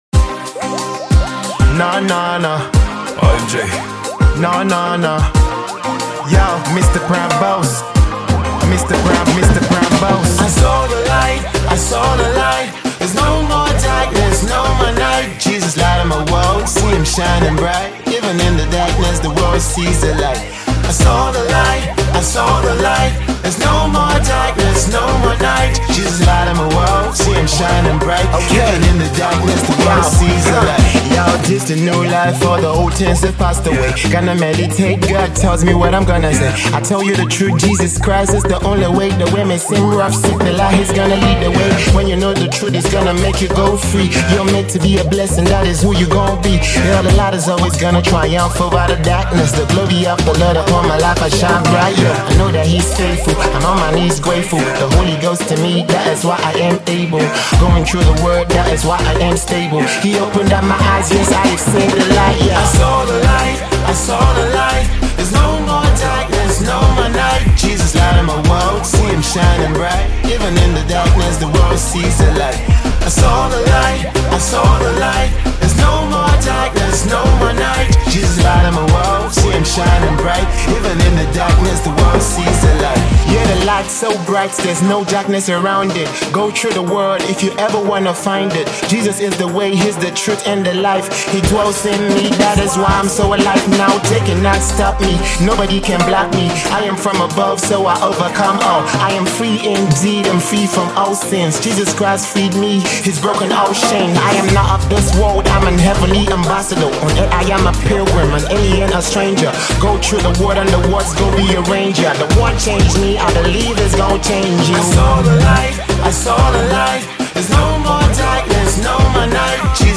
Ballad
Christian rap preacher, a contemporary Gospel artist